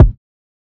Kick (U See).wav